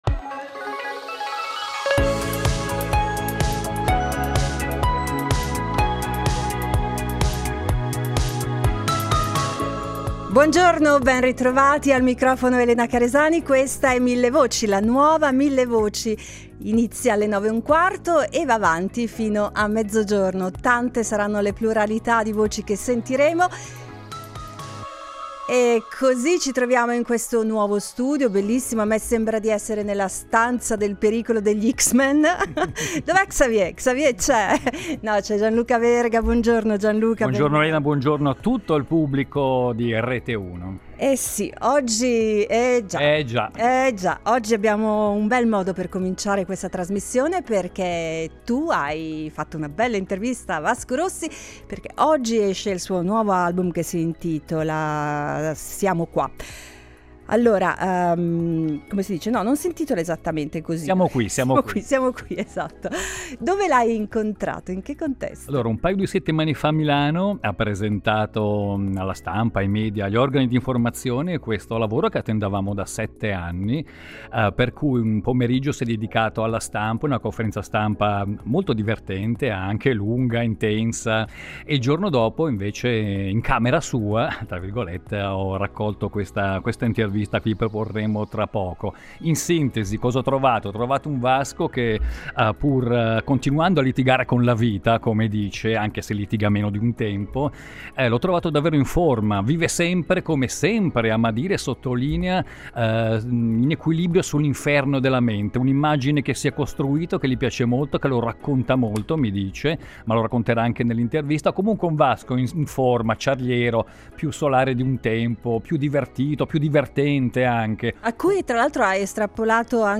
intervista audio e video seguita da apertura al pubblico per commenti a caldo e premiazione del messaggio più bello.